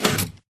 Sound / Minecraft / tile / piston / out.ogg